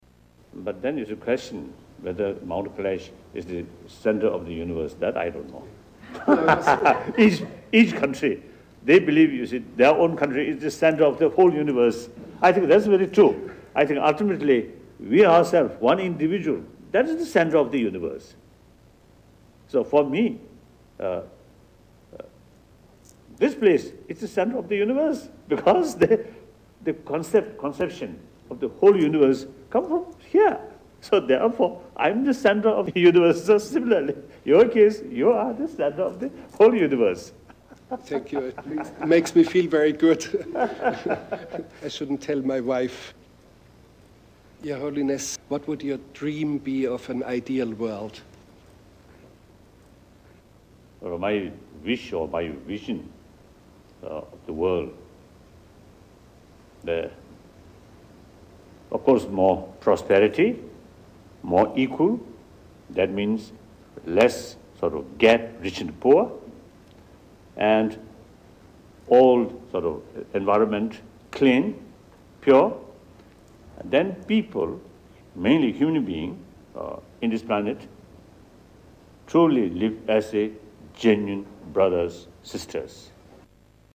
Interviews the Dalai Lama